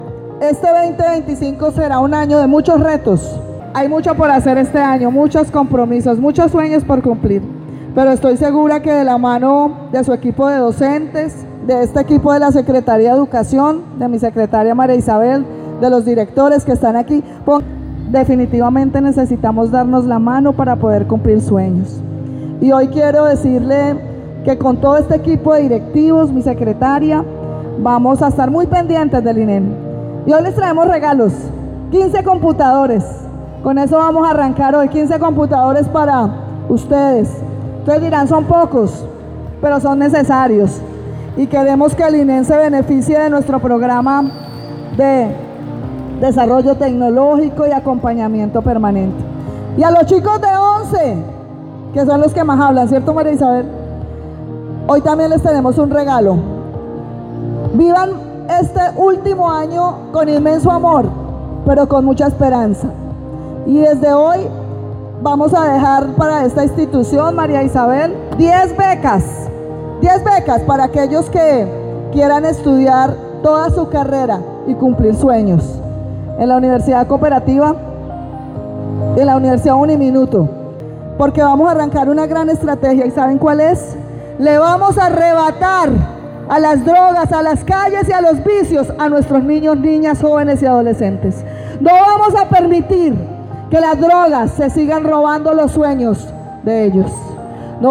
A través de una emotiva jornada en el colegio INEM, la alcaldesa Johana Aranda acompañó el regreso a clases de los más de 80.000 estudiantes ibaguereños (60.050 en colegios públicos y 23.519 en el sector privado), que ingresaron a las aulas el día de hoy, destacando la labor de docentes e invitando a los padres de familia a matricular a los niños y jóvenes que hacen falta.
Alcaldesa-Johana-Aranda-sobre-regreso-a-clases.mp3